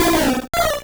Cri de Piafabec dans Pokémon Rouge et Bleu.